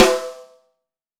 • Long Snare B Key 40.wav
Royality free steel snare drum sample tuned to the B note. Loudest frequency: 1607Hz
long-snare-b-key-40-9SU.wav